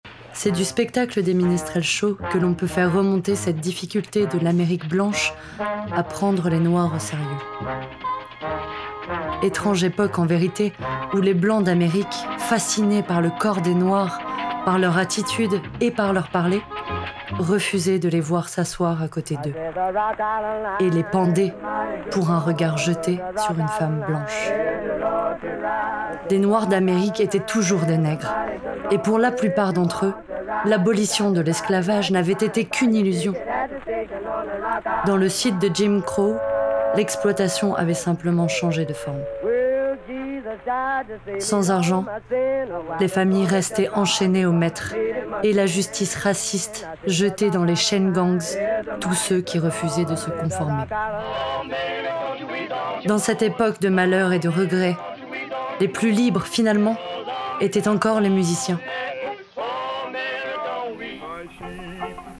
IMDA - Voix off - Black Music " Des chaines de fer aux chaines en or"